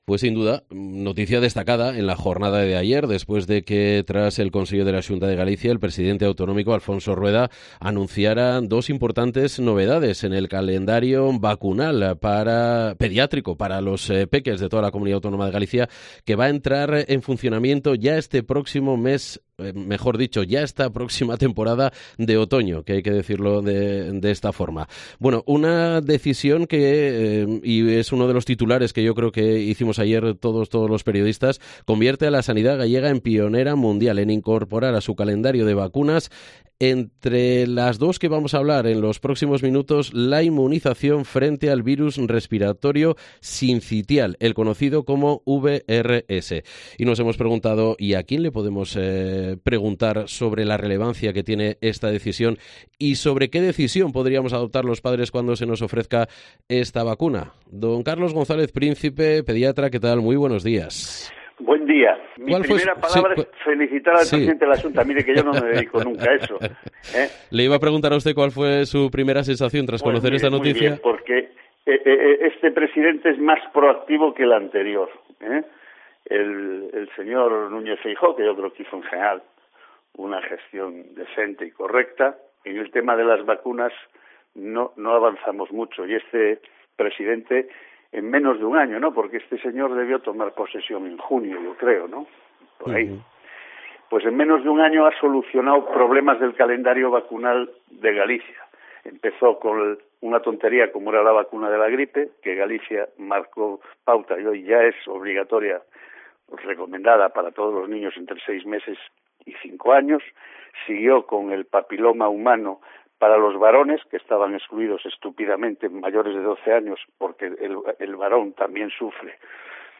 Entrevista en Mediodía COPE Galicia